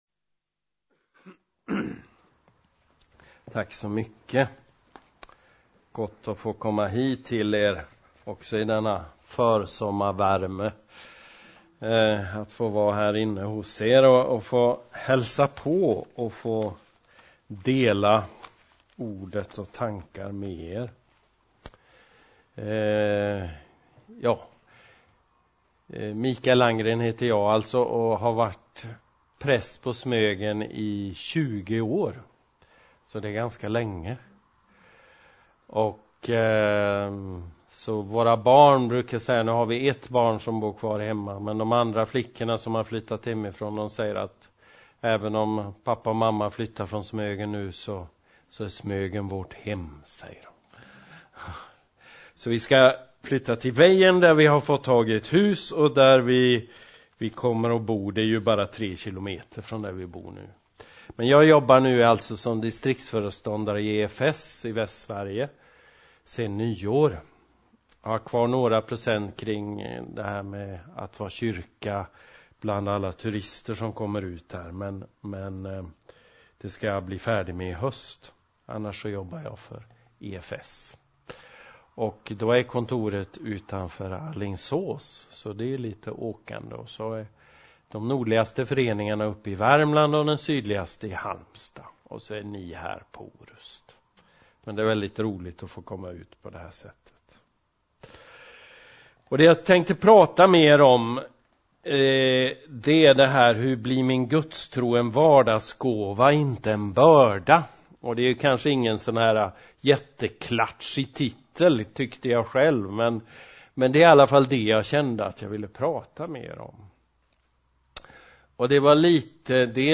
föredrag